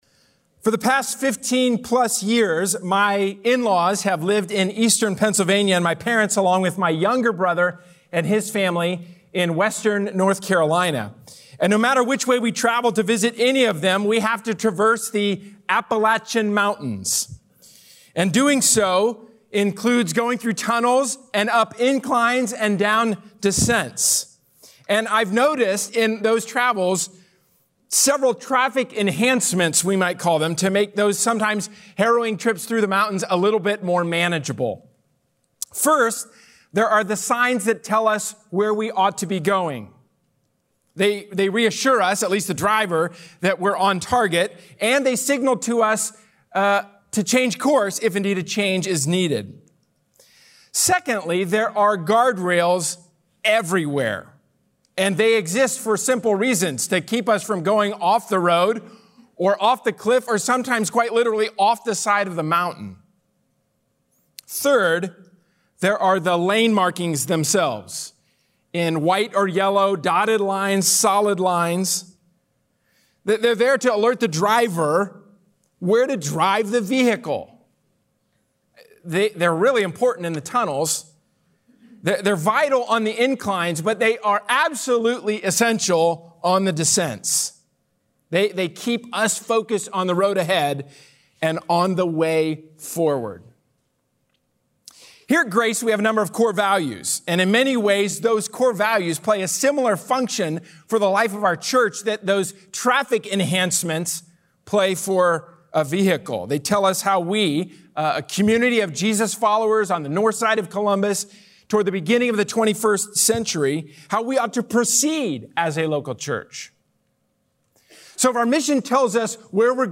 Sermon: "Generosity Panel" from Panel Discussion • Grace Polaris Church